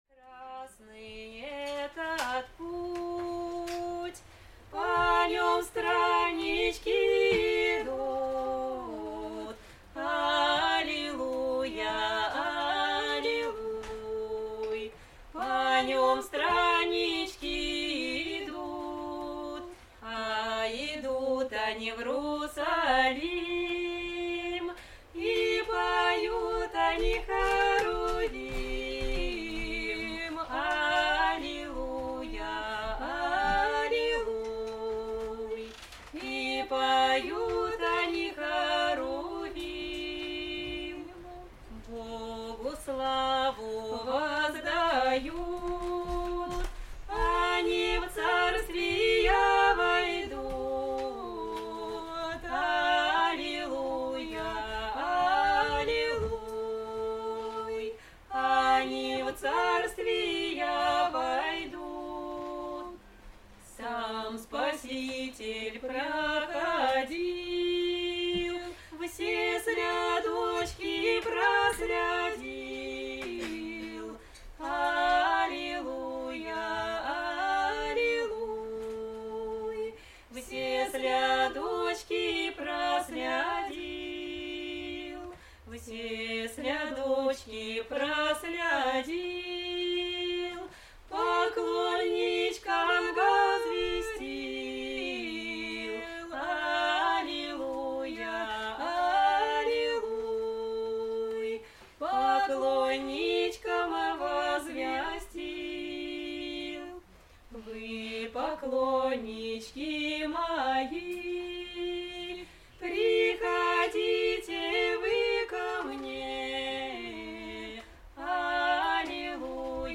Aудиокнига Духовные стихи о Иерусалиме Автор Полина Терентьева.